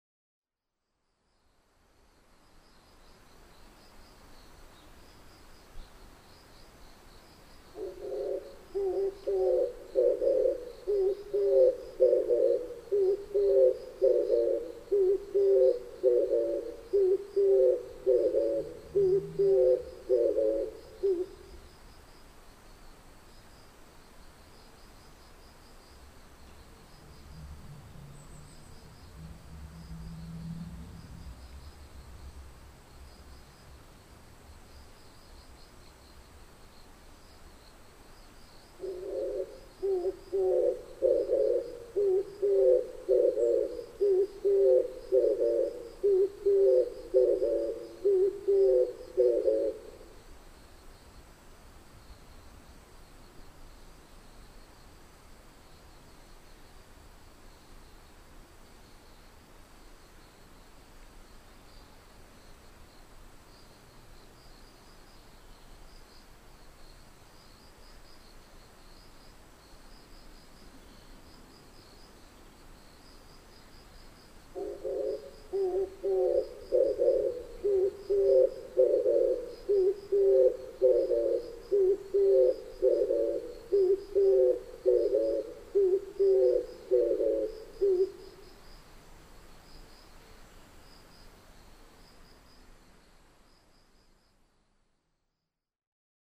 キジバト　Streptoperia orientalisハト科
日光市稲荷川中流　alt=740m
Mic: Panasonic WM-61A  Binaural Souce with Dummy Head
頭上の電線に止まり鳴いています。